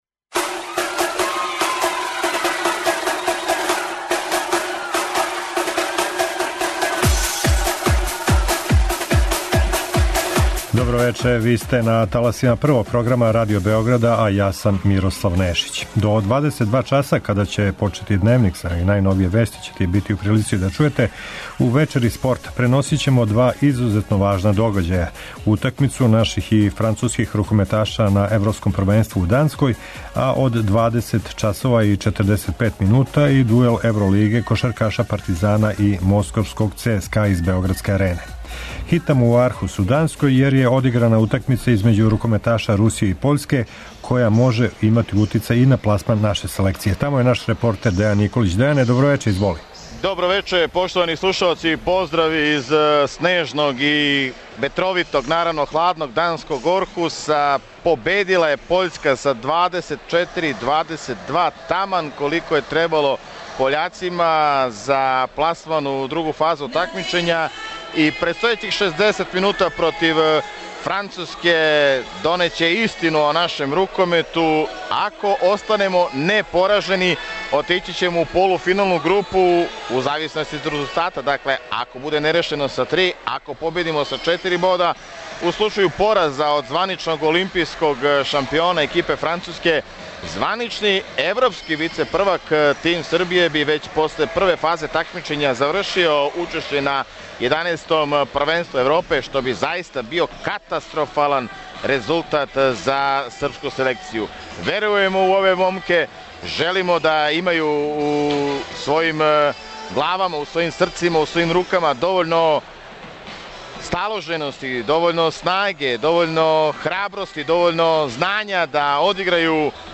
Комбиновано преносимо рукометну утакмицу Србија-Француска са Европског првенства у Данској и кошаркашки меч Партизан-ЦСКА у трећем колу Топ 16 Евро-лиге.